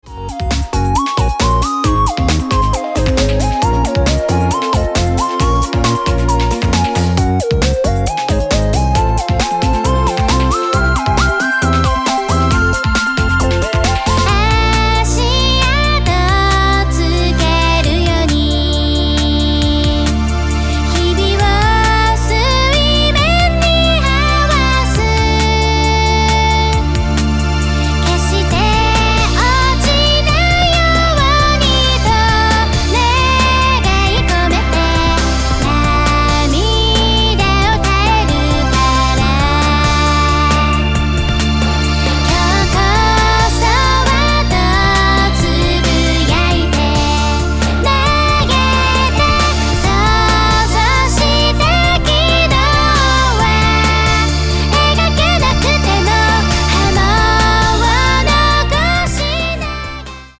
楽曲デモ